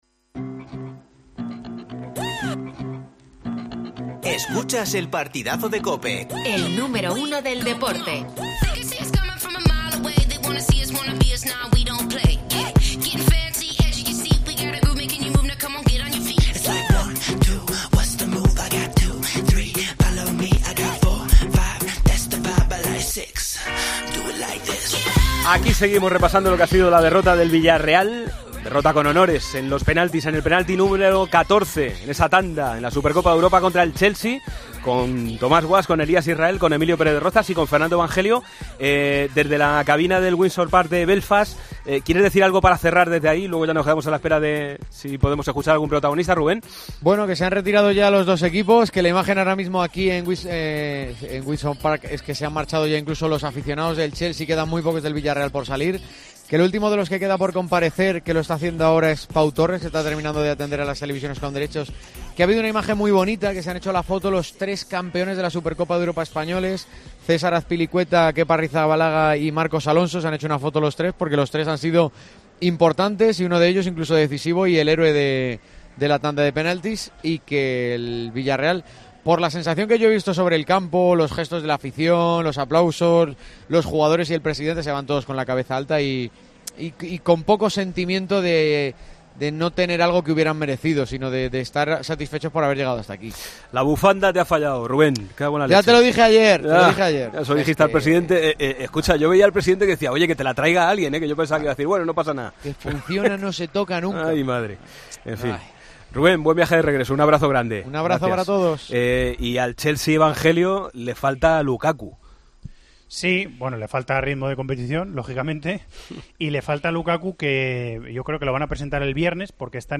AUDIO: Entrevista a Pau Torres.